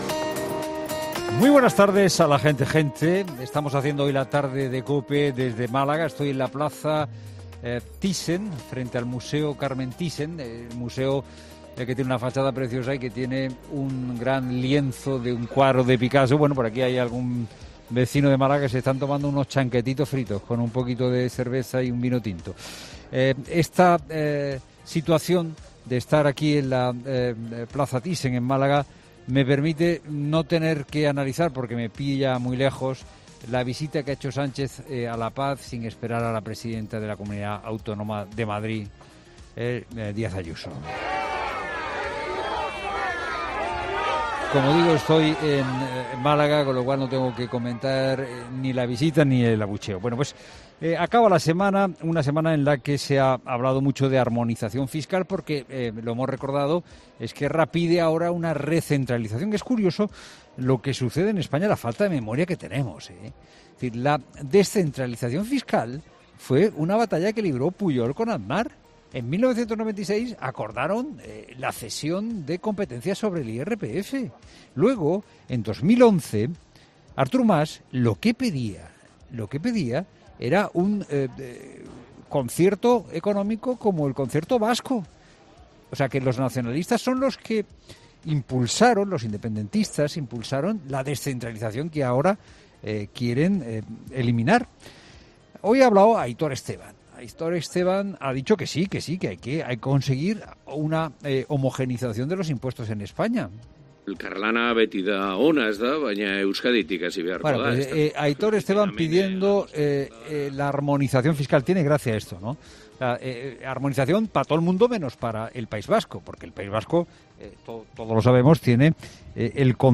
Estamos haciendo 'La Tarde' de COPE desde Málaga, frente al Museo Carmen Thyssen.